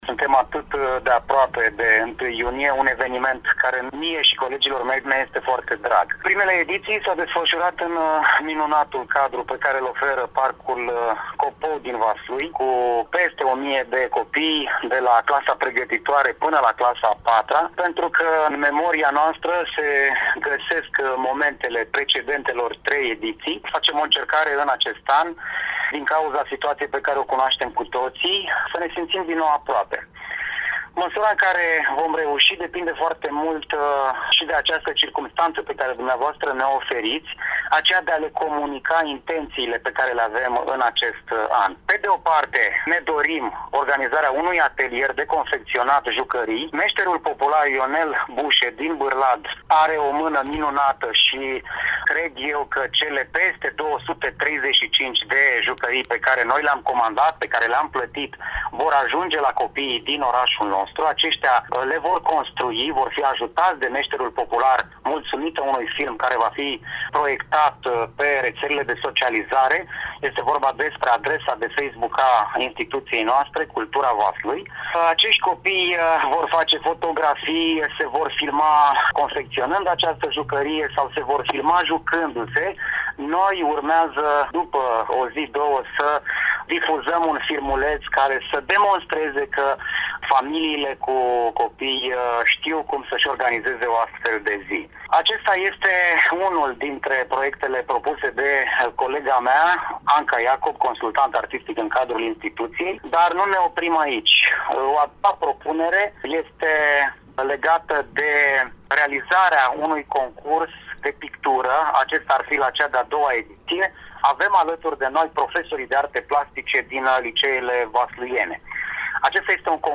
Invitatul emisiunii „Recreația mare”